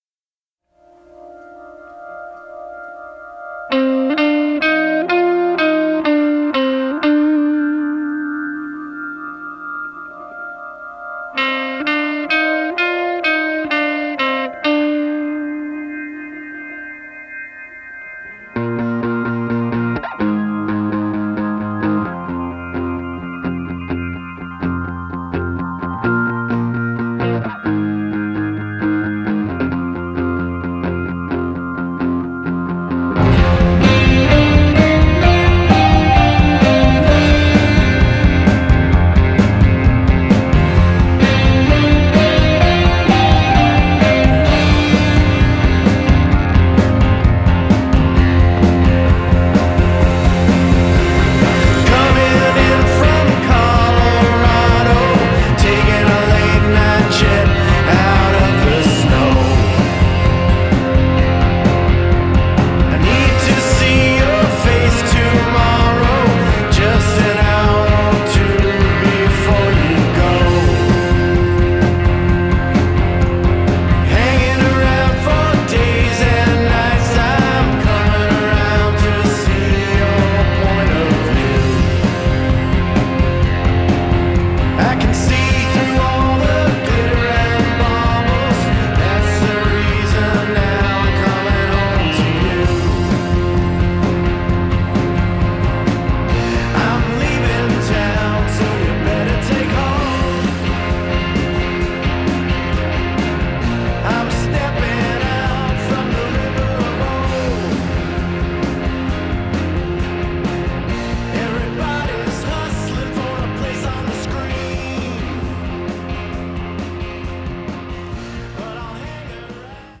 a conventional pop/rock structure